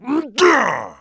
client / bin / pack / Sound / sound / monster / maenghwan / attack_1.wav
attack_1.wav